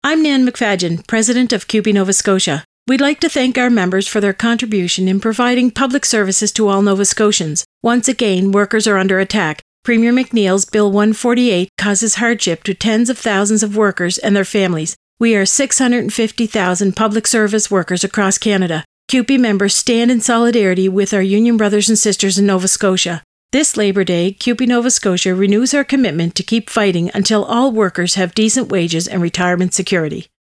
Radio ad: Labour Day message - CUPE Nova Scotia